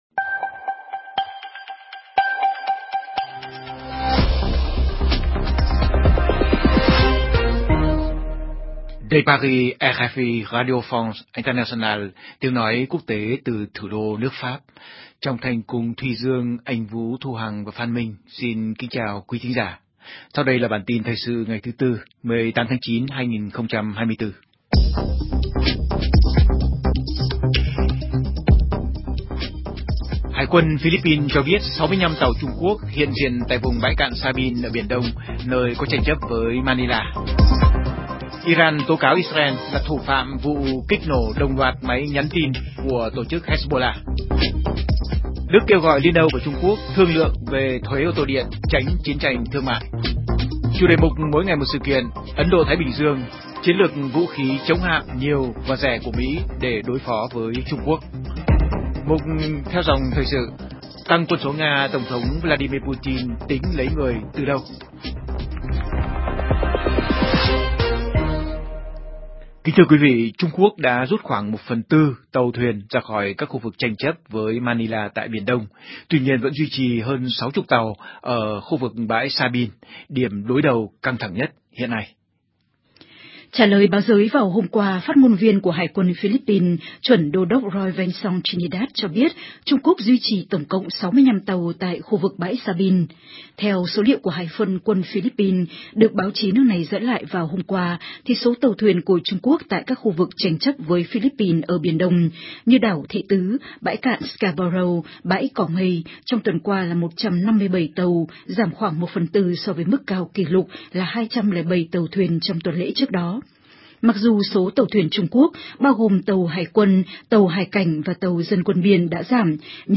CHƯƠNG TRÌNH PHÁT THANH 60 PHÚT Xem tin trên website RFI Tiếng Việt Hoặc bấm vào đây để xem qua Facebook